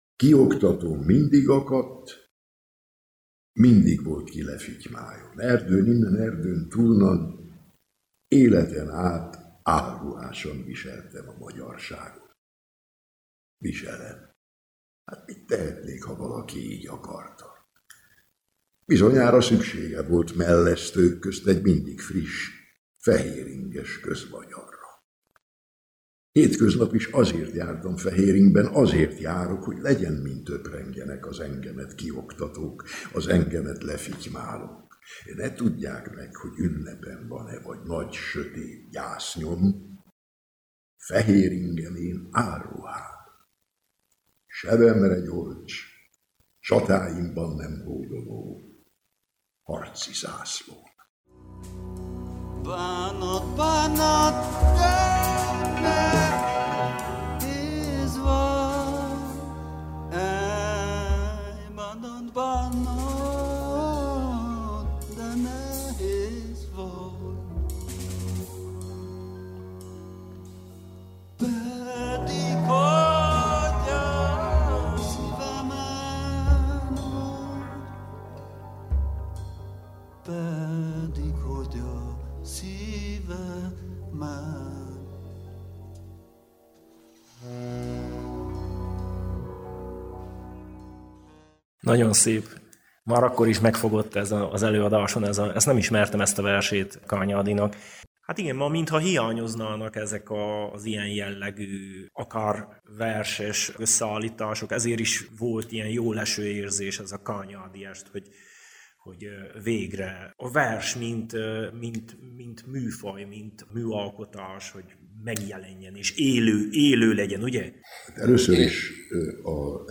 Nemes Levente színművészt hallhatják: